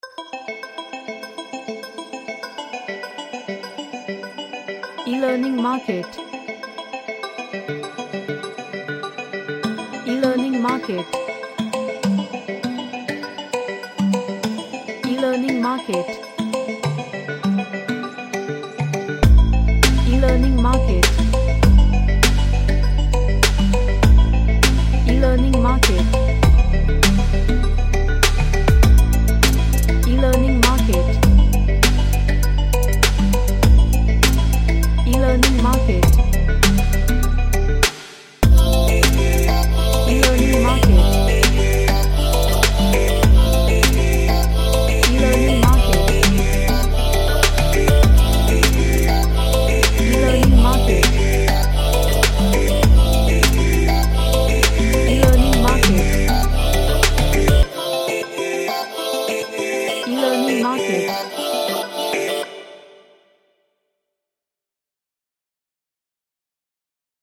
A mystical type cinematic track
Sci-Fi / Future